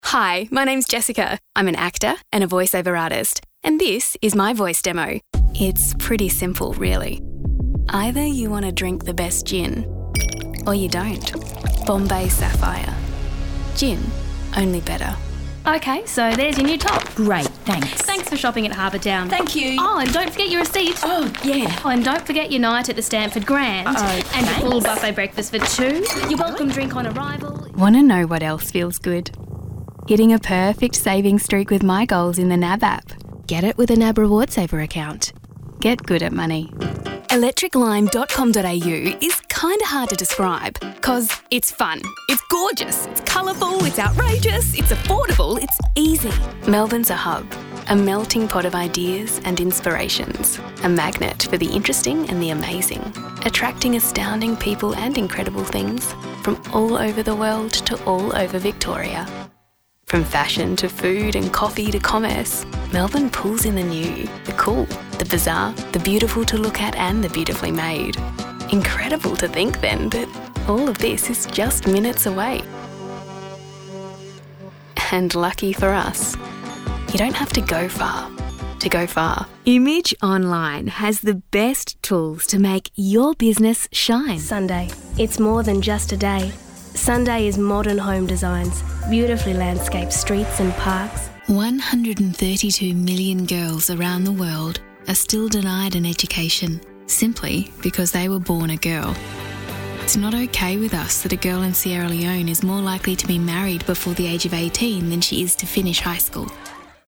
I have a clear, confident and conversational voice with a natural British accent. My delivery feels relaxed and engaging, with a friendly tone that works well for both informative and entertaining content.